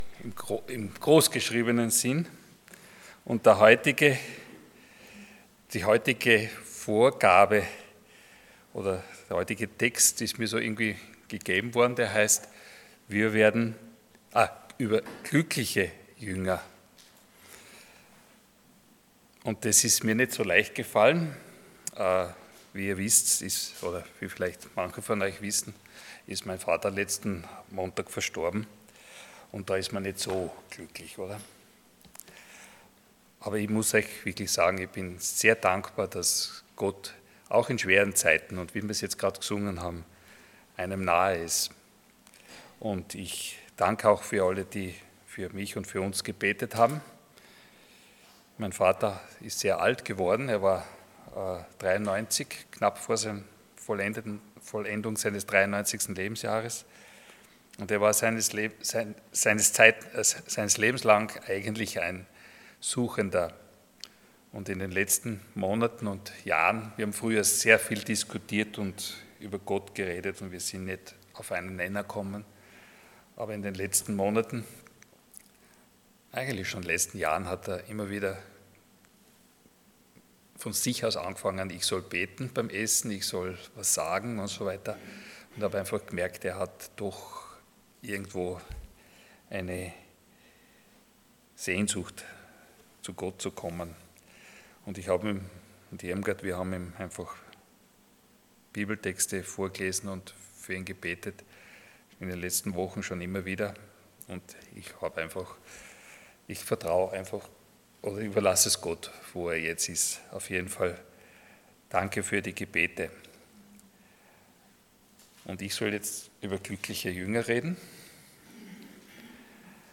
Passage: Luke 6:17-26 Dienstart: Sonntag Morgen